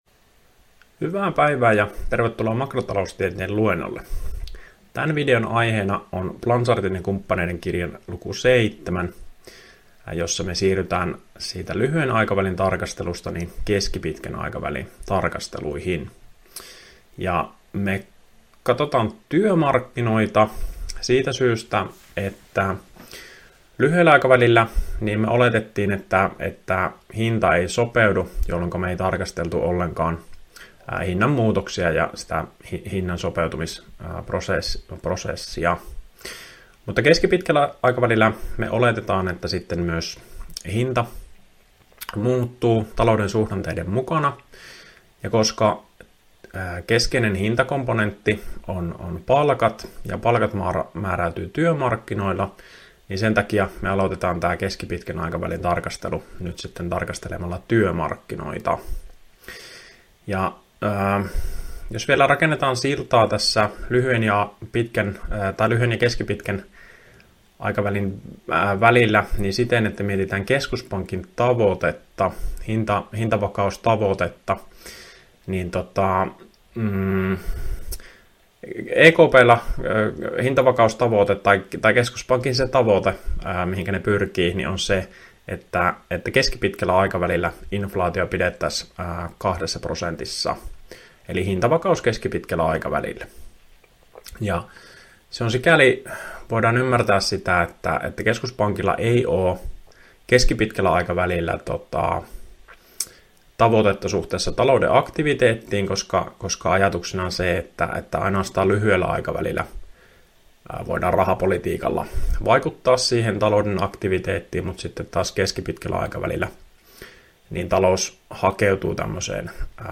Opintojakson "Makrotaloustiede I" suhdanneosion 6. opetusvideo.